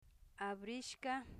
abrishca open Part of Speech adjective Acquisition Method Conversations Etymology Spanish Phonological Representation a'bɾiʃka open abierto [Spanish] pascashca [Quichua] (Part of) Synonym (for) abierto Example 24: Ese corralca abrishcami.